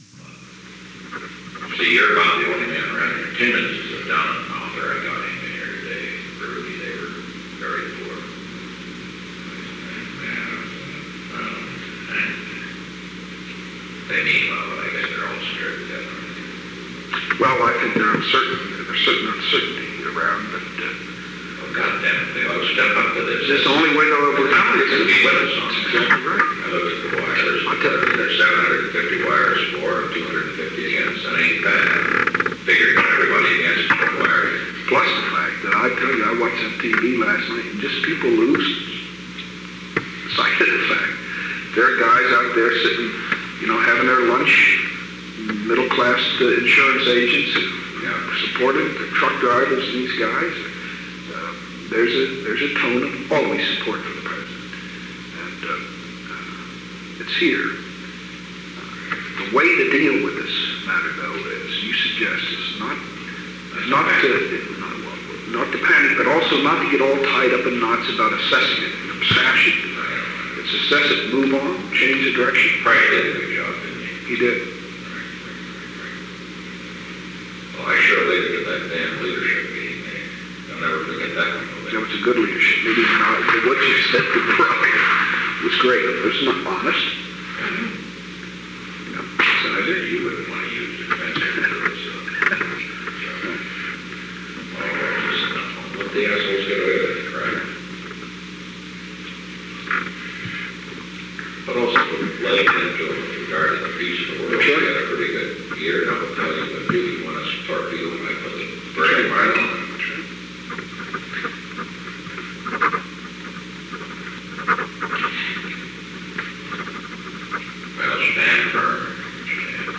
Secret White House Tapes
Location: Oval Office
The President met with Ronald L. Ziegler.
conversation was in progress.